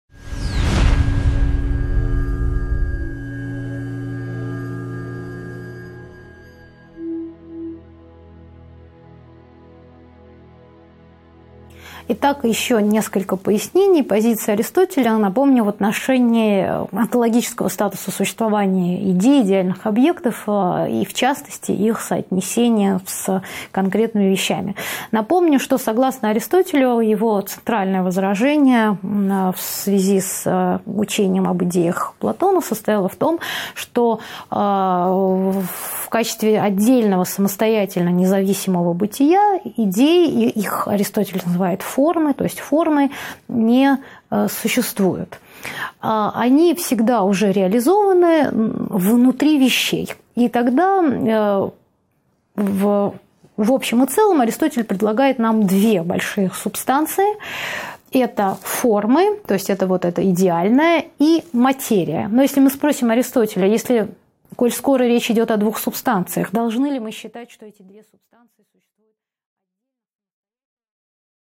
Аудиокнига 2.11 Онтология Аристотеля (окончание). Выводы к лекции | Библиотека аудиокниг